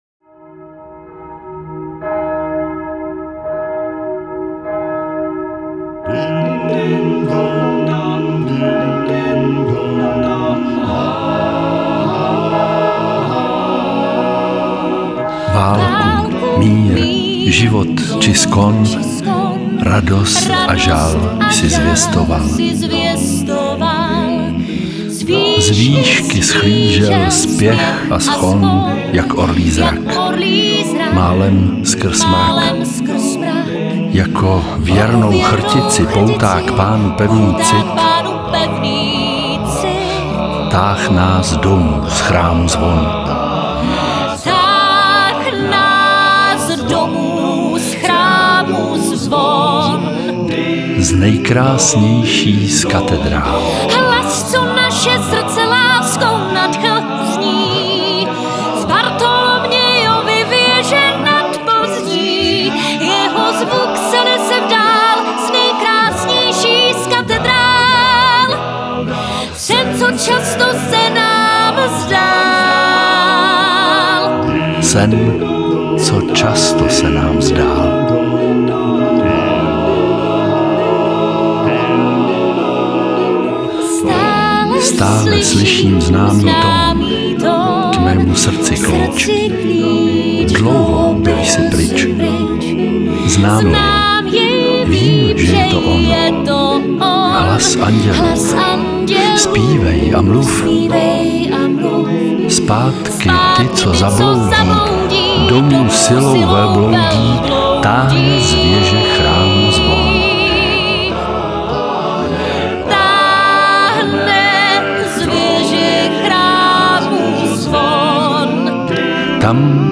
s vokálním kvintetem